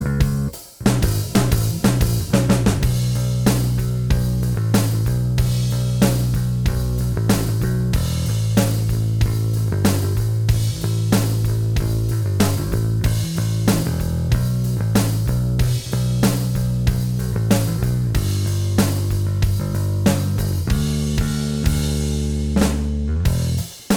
Minus All Guitars Rock 4:09 Buy £1.50